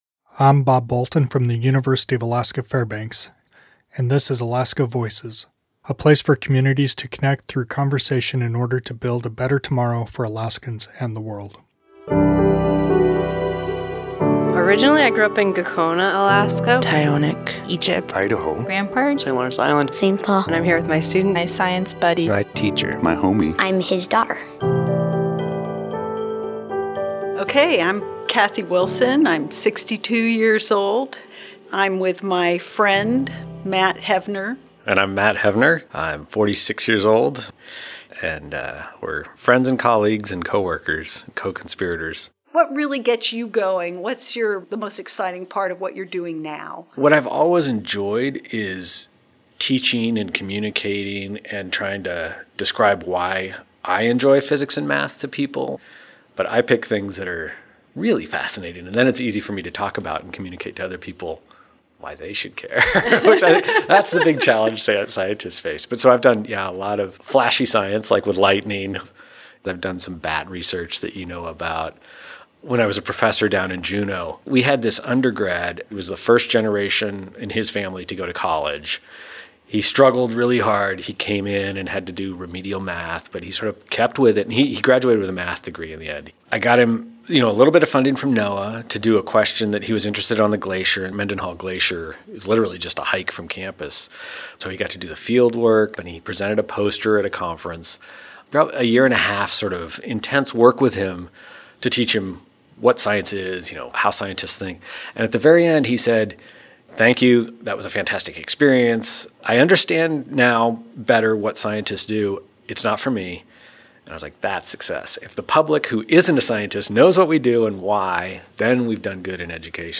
This interview was recorded in collaboration with StoryCorps.